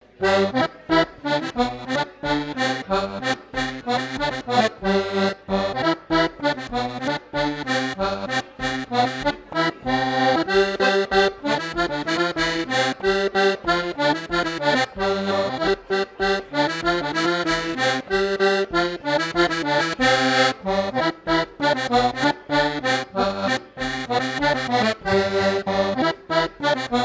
danse : scottich trois pas
Fête de l'accordéon
Pièce musicale inédite